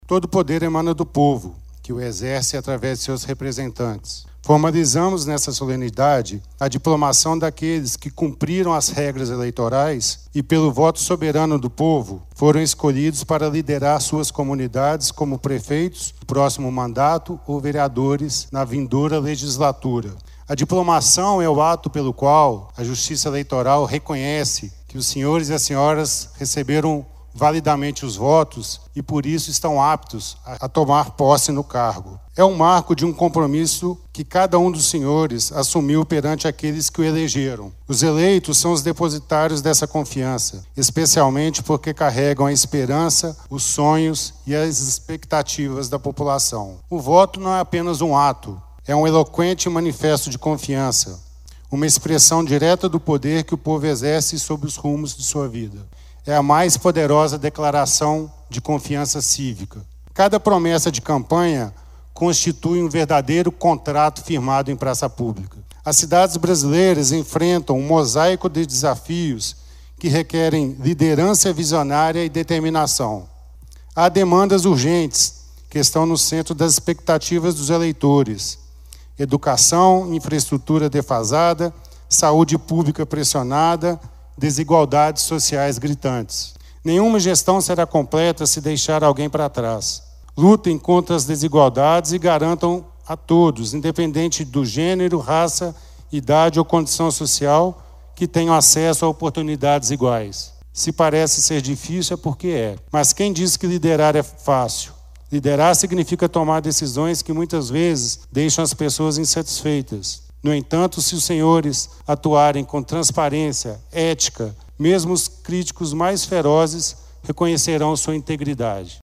A diplomação dos eleitos ocorreu na noite desta sexta-feira (13), na Câmara Municipal de Pará de Minas, em solenidade presidida pela juíza Gabriela Andrade de Alencar Ramos.
O promotor eleitoral, Charles Daniel França Salomão, proferiu um discurso esclarecedor para os eleitos e eleitores.